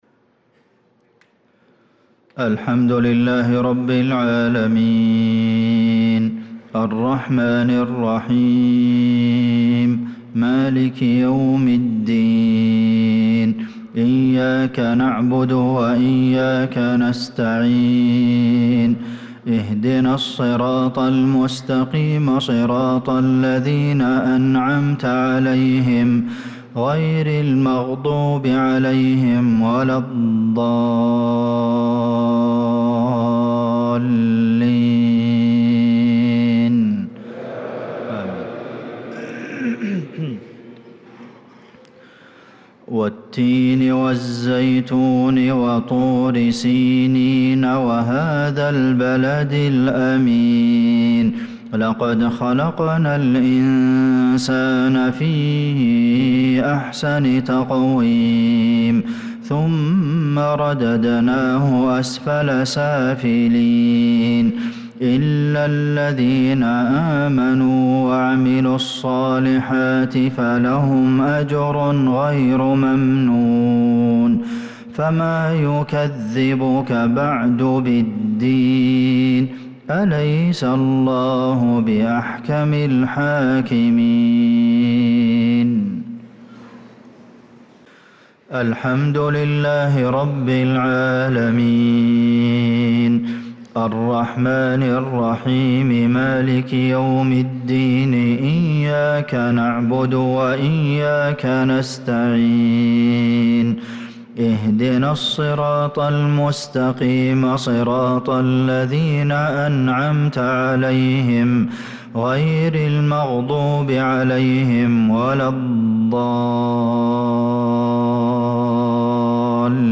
صلاة المغرب للقارئ عبدالمحسن القاسم 6 شوال 1445 هـ